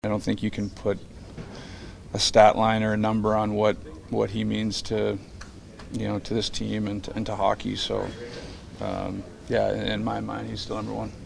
Crosby says no matter how many points he has scored or end up scoring, Mario will always be number one.